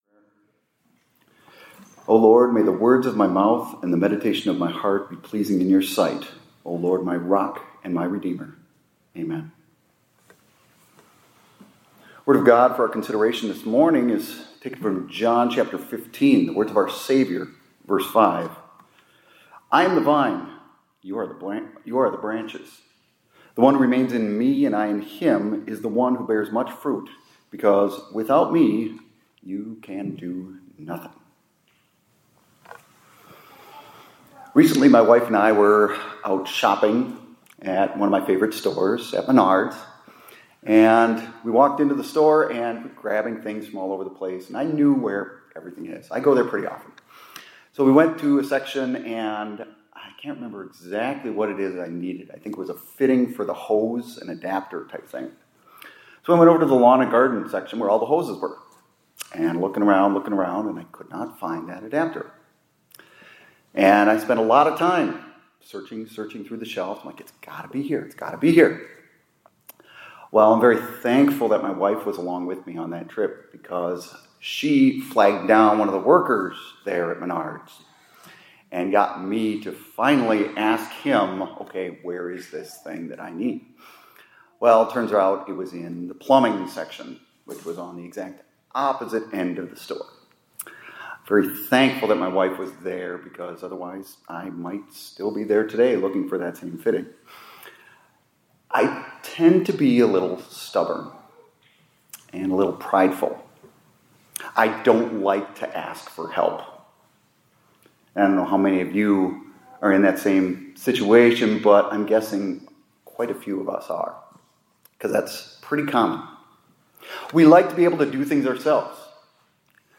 Hymn: LSB 556, st. 1,3,4 : Dear Christians, One and All, Rejoice